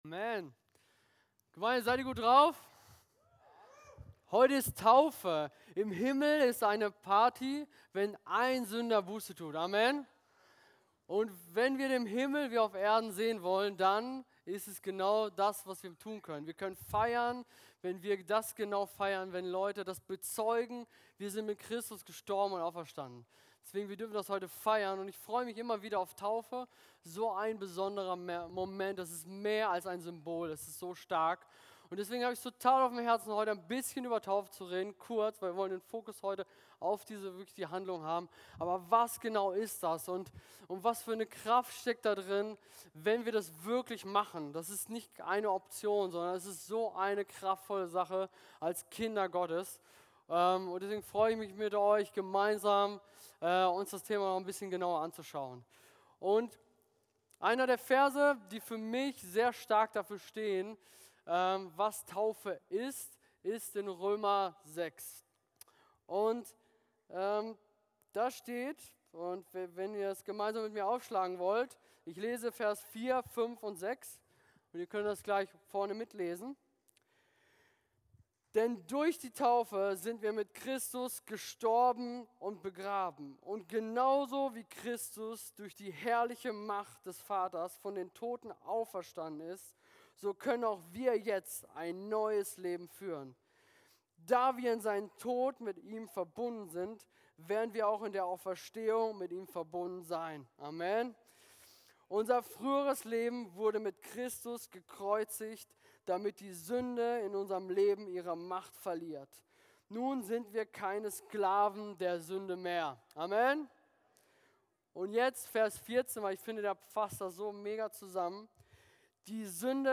Podcast unserer Predigten